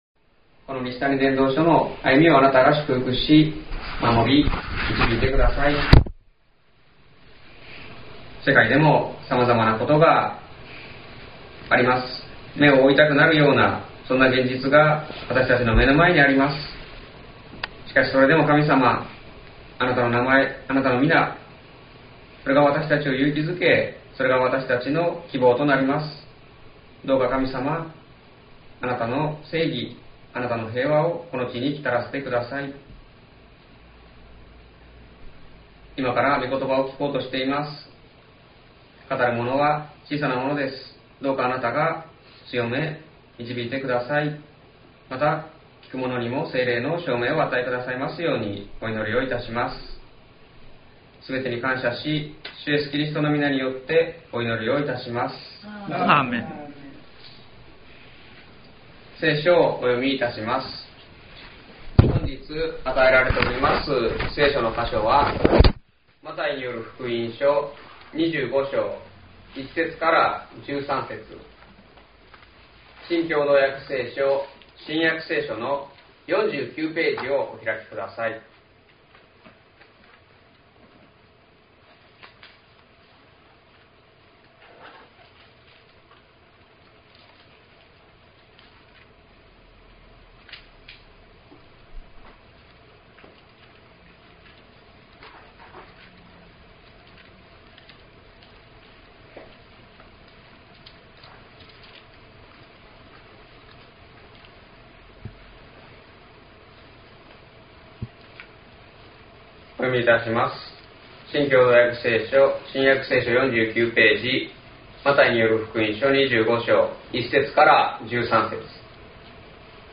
西谷教会。説教アーカイブ。
音声ファイル 礼拝説教を録音した音声ファイルを公開しています。